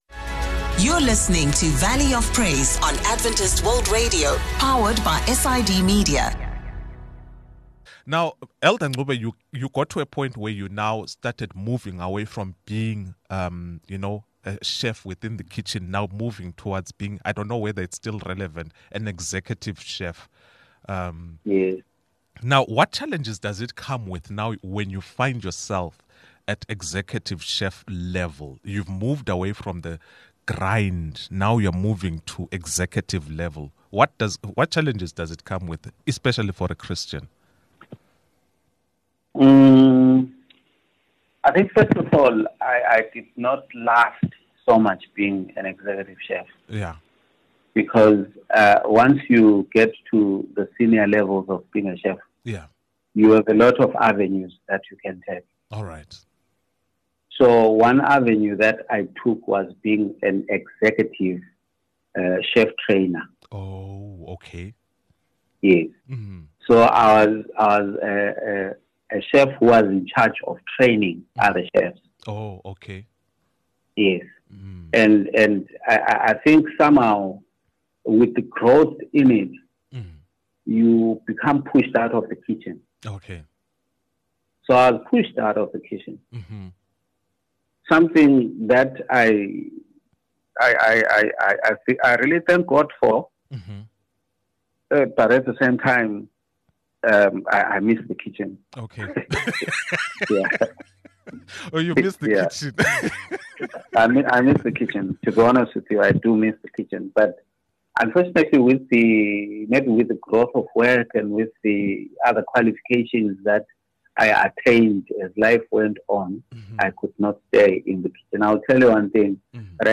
From overcoming obstacles to embracing faith in the workplace, we discuss it all. Get ready for a candid conversation on living out your faith in your profession.